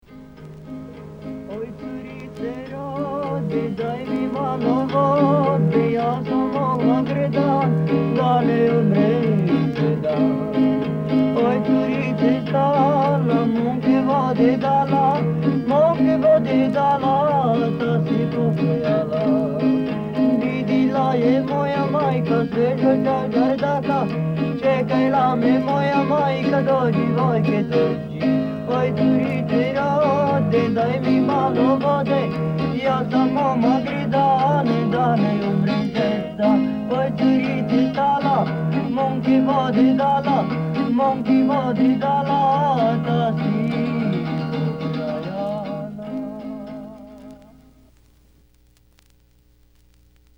NORD E CENTRO ITALIA - DALLE RICERCHE DI ALAN LOMAX )1954)
09-gypsy song (canto di zingaro).mp3